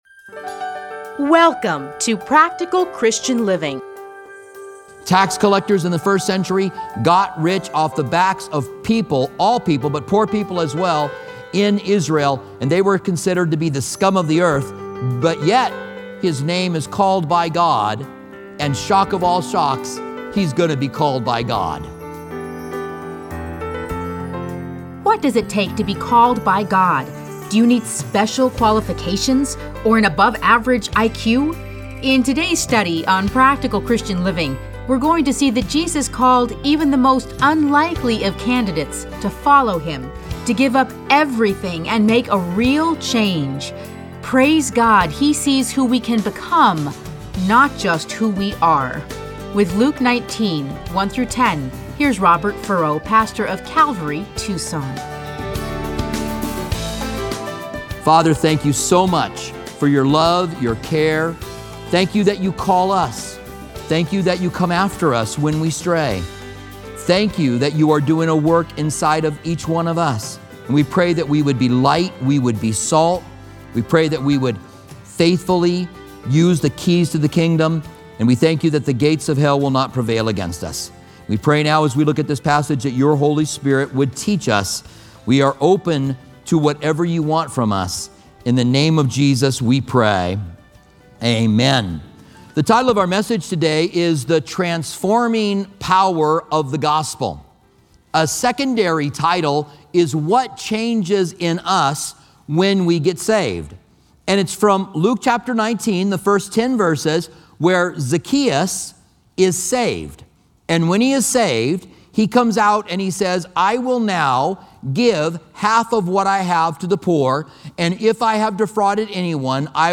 Listen to a teaching from Luke 19:1-10.